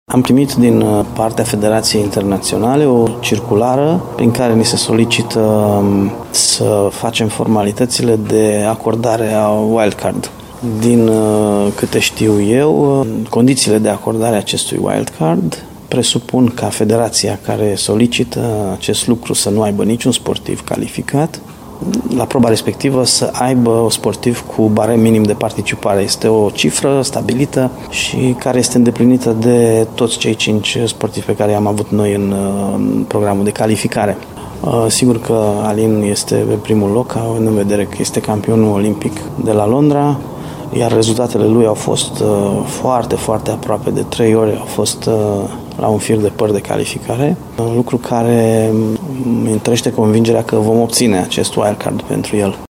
Federația internațională de Tir Sportiv este pregătită să ofere un wild card unui român, după cum explică antrenorul lui Alin, arădeanul Sorin Babii.
Radio Timișoara a fost la primul antrenament din această săptămână al lui Alin Moldoveanu, la Poligonul din Arad.